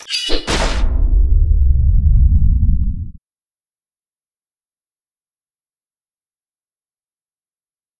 swingsword.wav